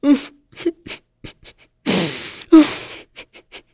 Glenwys-Vox_Sad.wav